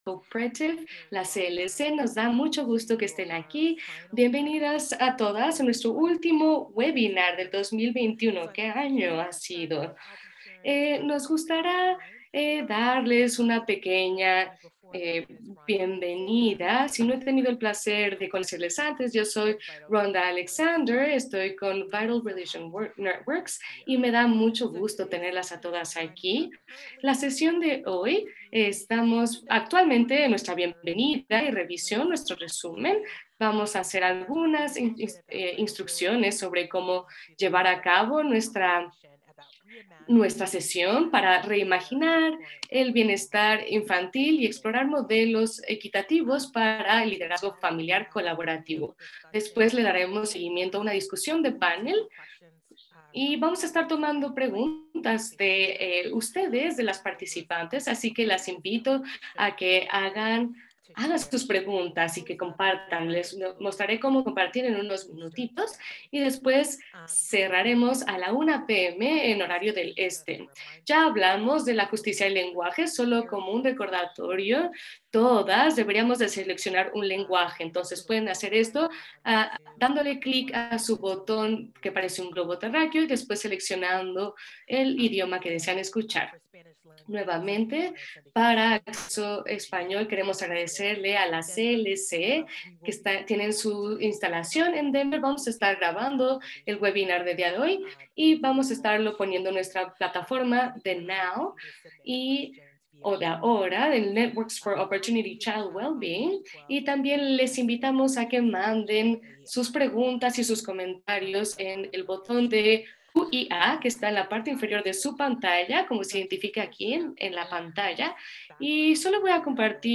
Webinar Archive: Reimagining Child Welfare: Equitable Models for Collaborative Family Leadership